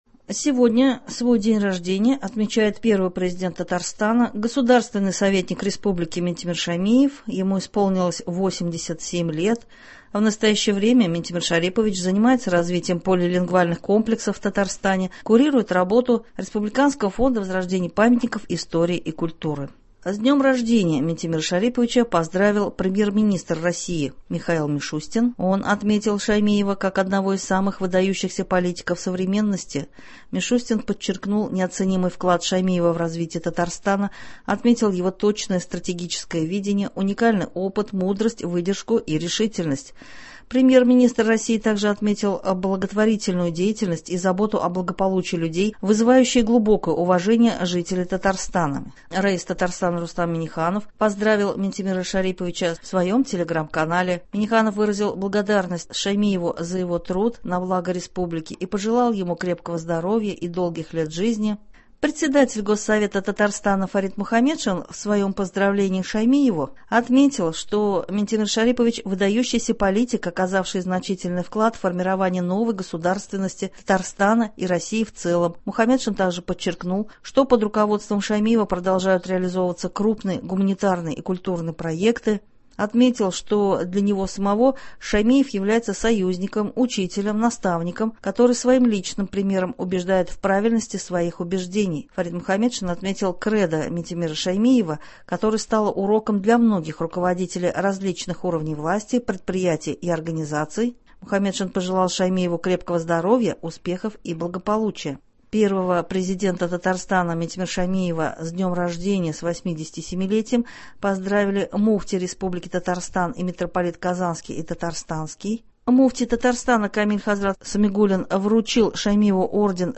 Новости (20.01.24)